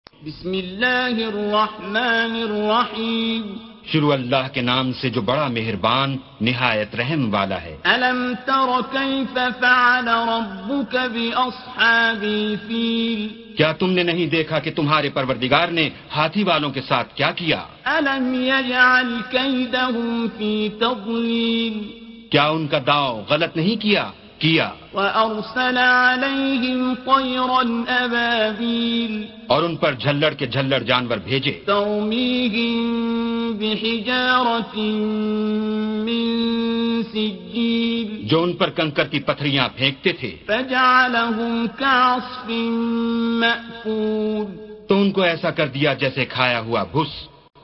Surah Sequence تتابع السورة Download Surah حمّل السورة Reciting Mutarjamah Translation Audio for 105. Surah Al-F�l سورة الفيل N.B *Surah Includes Al-Basmalah Reciters Sequents تتابع التلاوات Reciters Repeats تكرار التلاوات